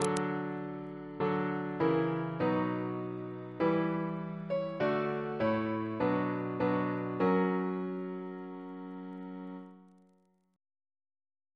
Single chant in B minor Composer: Thomas Tallis (1505-1585) Reference psalters: ACB: 363